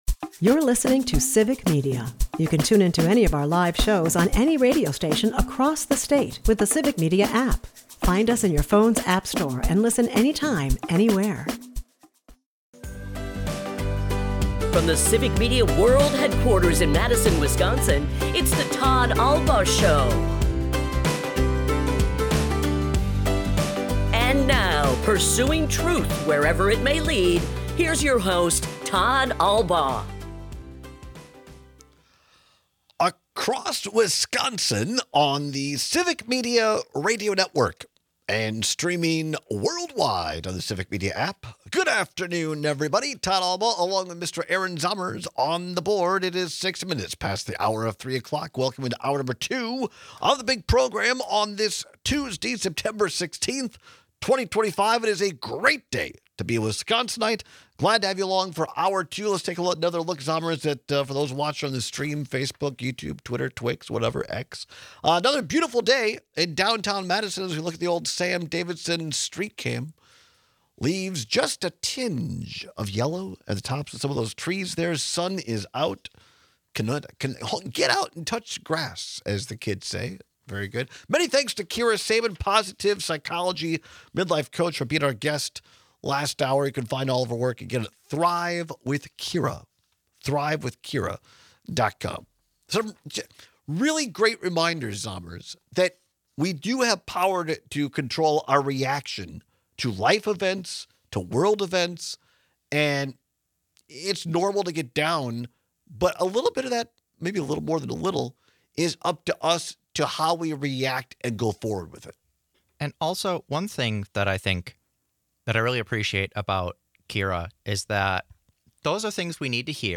We take your calls and texts on whether you’d rather pay as you go or all at once.
airs live Monday through Friday from 2-4 pm across Wisconsin